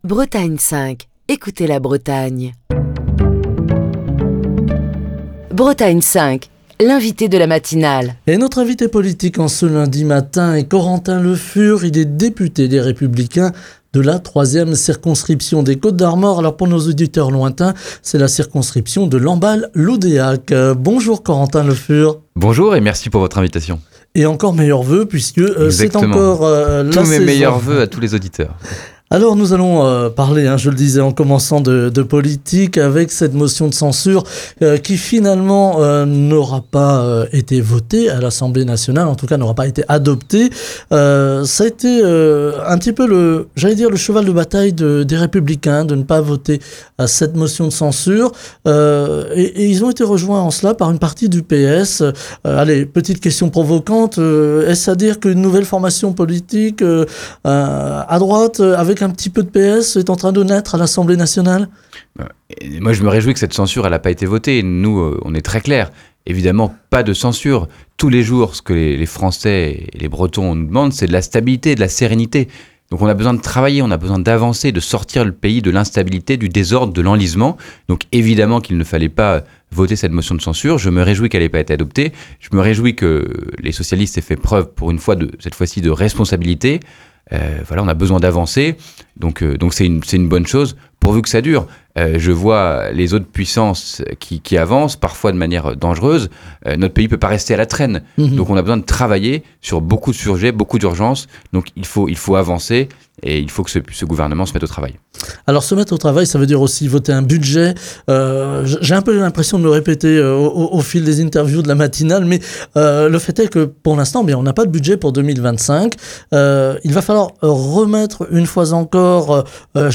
Ce lundi, Corentin Le Fur, député Les Républicains de la 3e circonscription des Côtes-d’Armor (Lamballe-Loudéac), était l'invité politique de Bretagne 5 Matin. Revenant sur la motion de censure qui a été rejetée la semaine dernière à l’Assemblée nationale, Corentin Le Fur a salué cette décision qu’il juge « sage » dans un contexte marqué par une instabilité politique croissante.